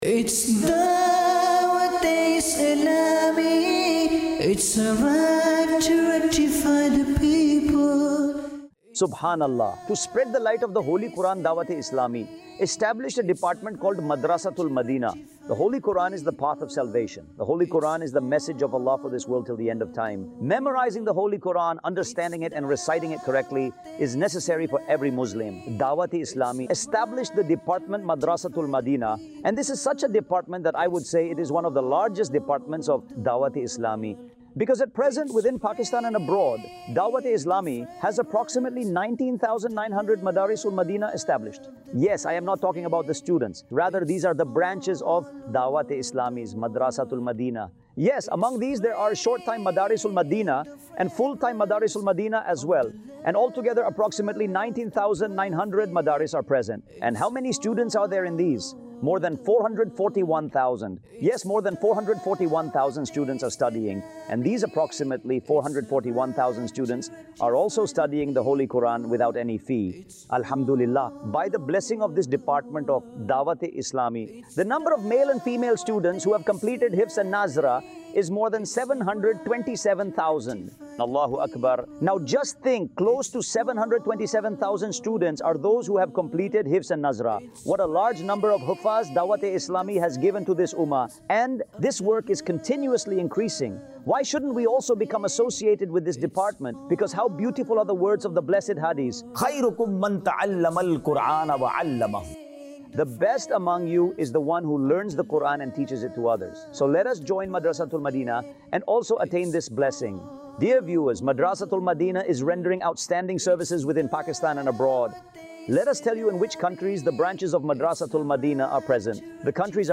Madrasa-Tul-Madinah | Department of Dawateislami | Documentary 2026 | AI Generated Audio